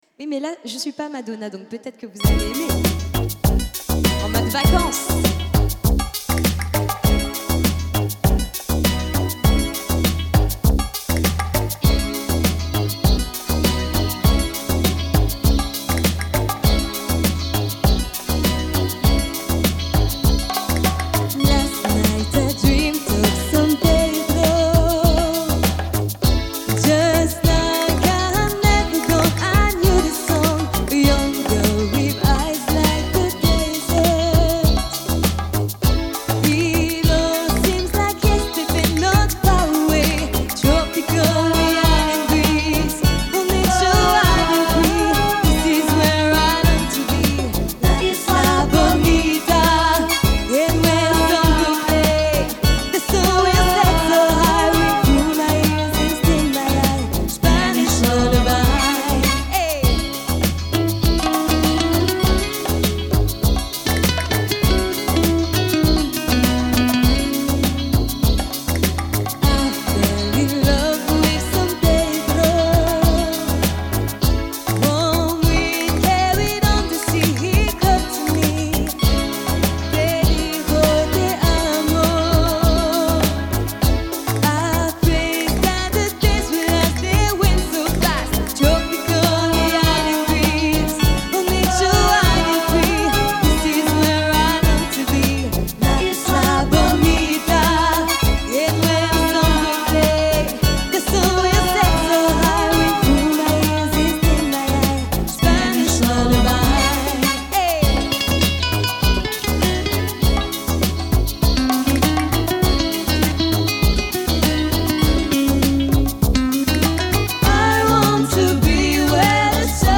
Programme d'orchestre de variété avec chanteuse et chanteur